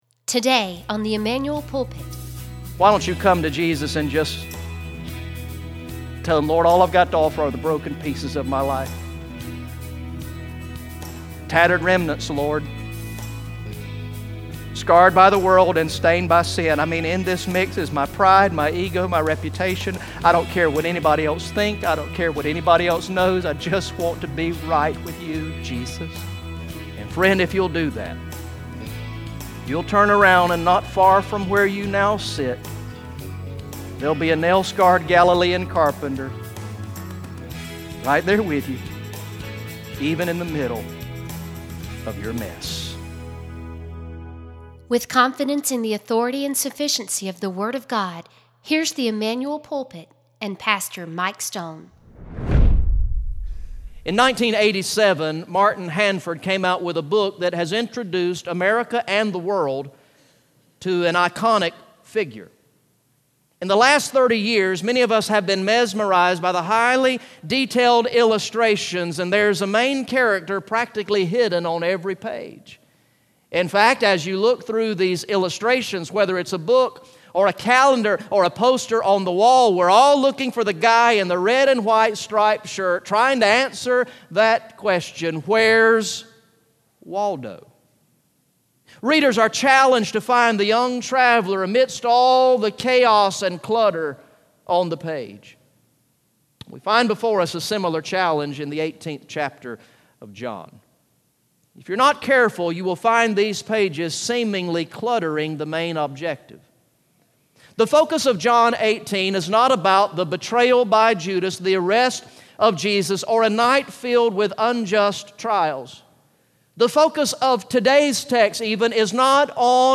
Message #68 from the sermon series through the gospel of John entitled "I Believe" Recorded in the morning worship service on Sunday, August 14, 2016